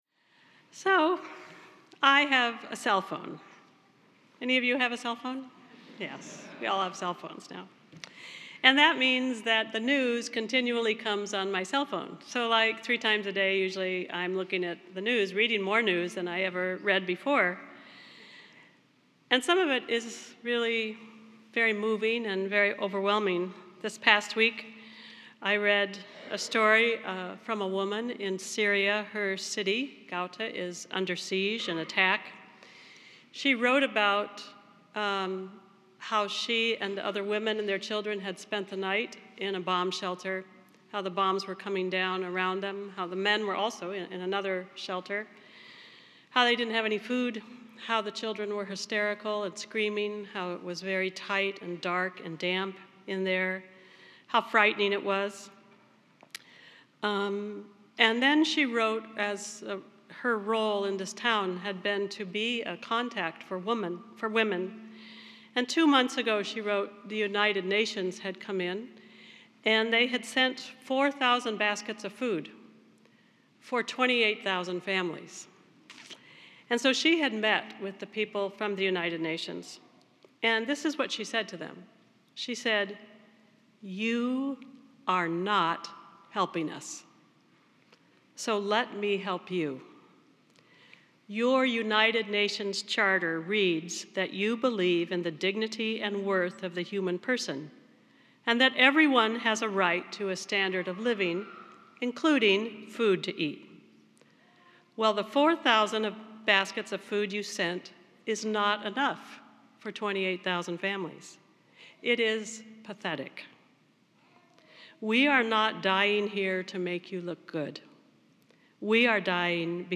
Spiritus Christi Mass March 4th, 2018